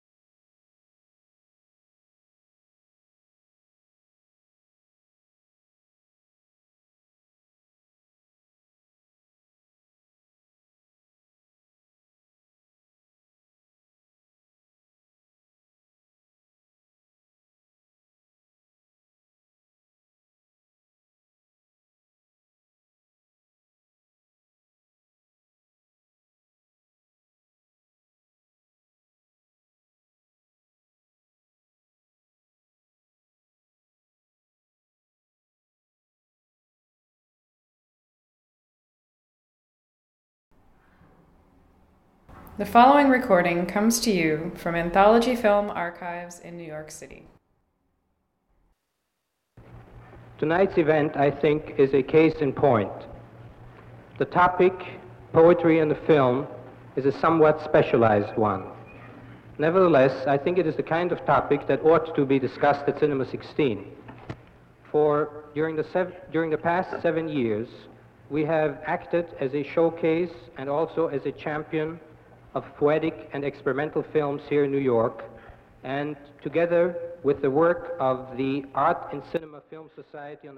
A showcase for sounds synchronized to experimental film from Philmont, NY and across the world.